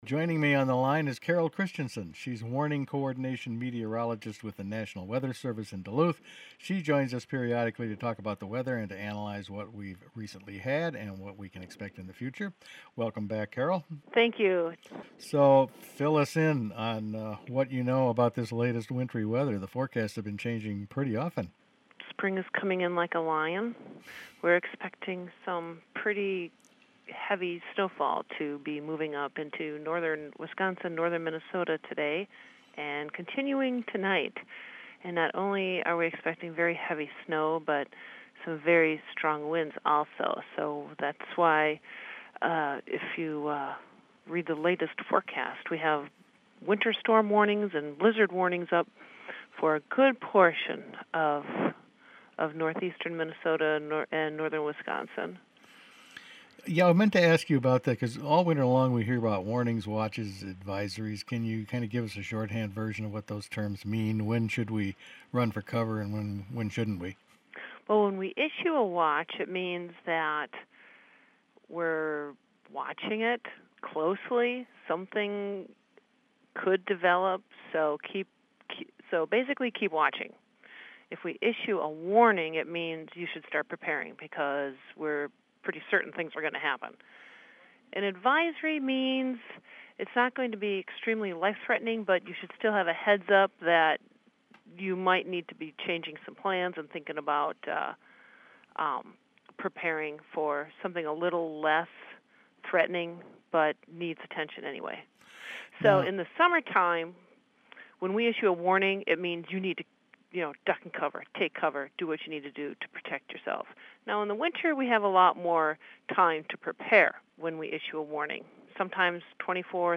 Meteorologist says winter storm is on the way